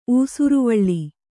♪ ūsuruvaḷḷi